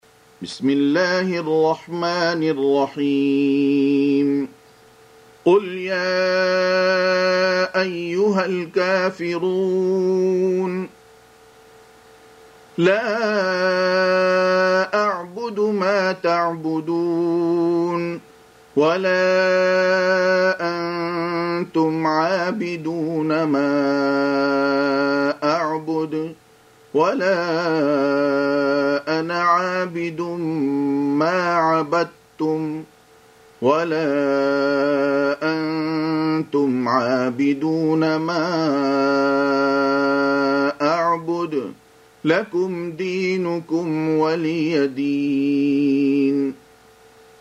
Surah Al-K�fir�n سورة الكافرون Audio Quran Tarteel Recitation
Surah Repeating تكرار السورة Download Surah حمّل السورة Reciting Murattalah Audio for 109.